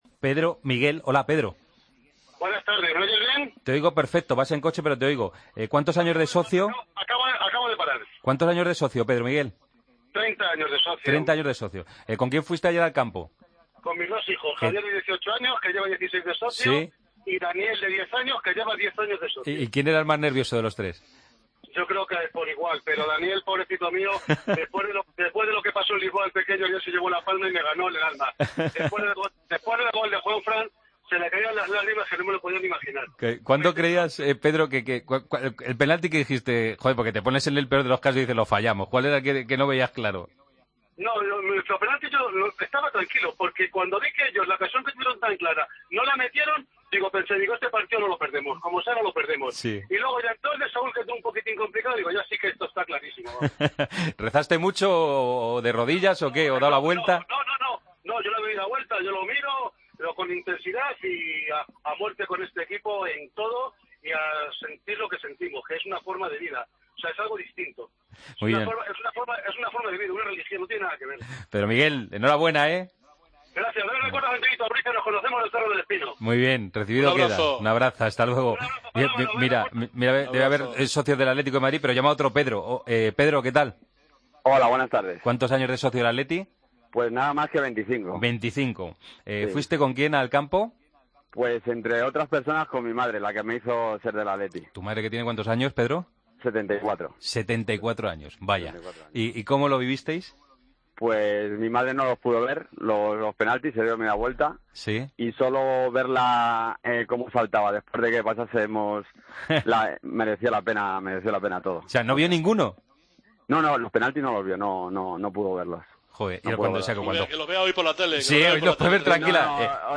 Dos oyentes, socios rojiblancos ambos, nos cuentan cómo vivieron en el Calderón la tanda de penaltis entre Atlético de Madrid y PSV Eindhoven.